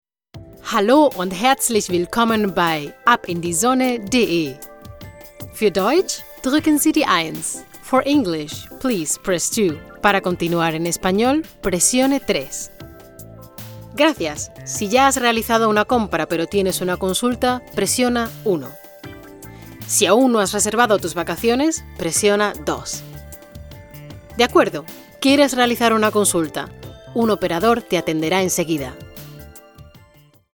Spanisch. 2014 SPRACHEN Deutsch: Flieβend (C2) Englisch: Flieβend (C1) Italienisch: Gut (A2) Spanisch: Muttersprache (europäisch) Akzente: Castellano, Andalusisch, Mexikanisch, Argentinisch Stimmalter: 30 – 40 Stimme: voll, frisch, dynamisch, warm, facettenreich, weich.
Sprechprobe: Werbung (Muttersprache):